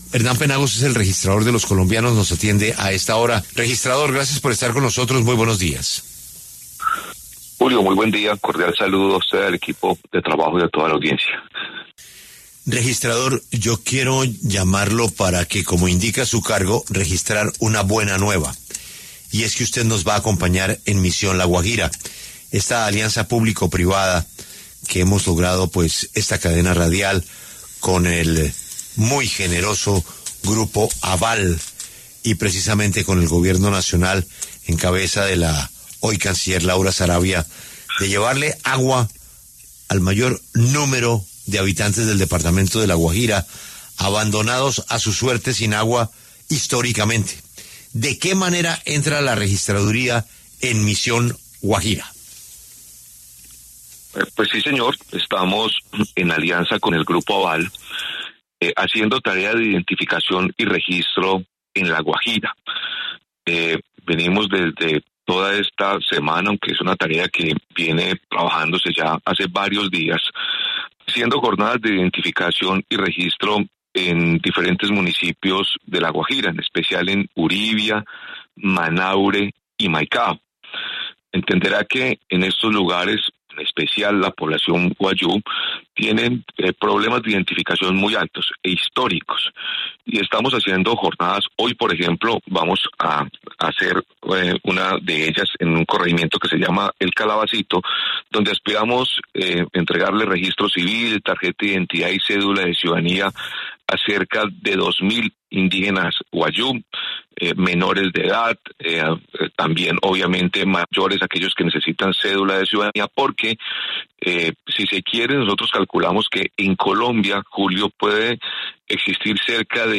Hernán Penagos, registrador nacional, pasó por los micrófonos de La W para hablar sobre el aporte que se está realizando desde la entidad al programa de Misión La Guajira.